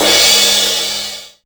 Linn Crash.WAV